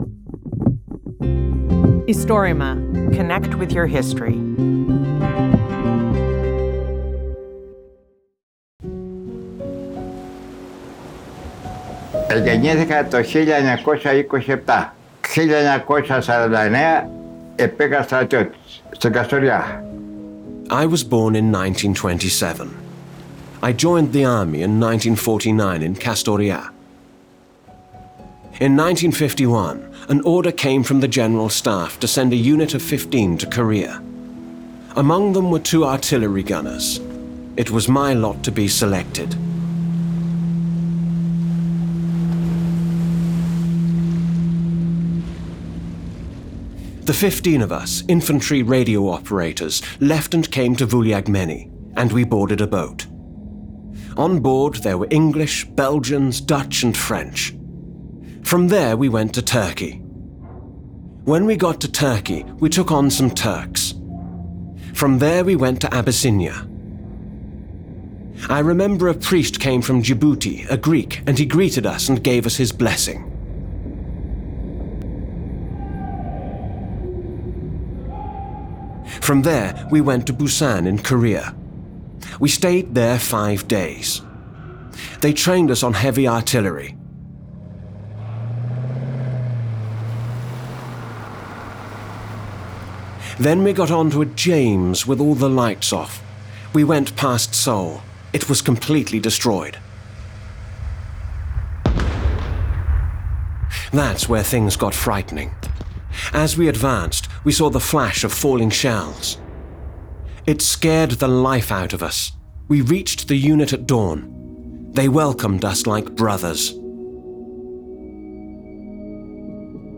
Interviewee
Voiceover
Istorima is the bigest project of recording and preserving oral histories of Greece.